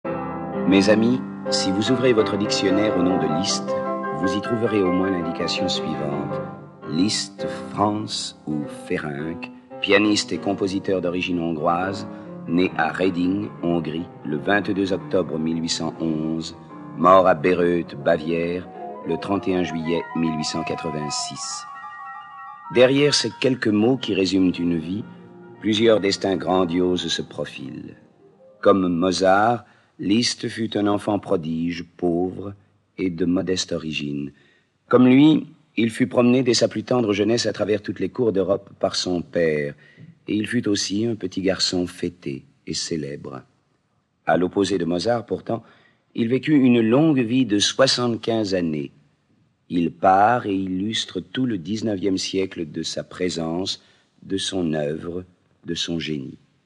Diffusion distribution ebook et livre audio - Catalogue livres numériques
Narrateur : Jacques Dacqmine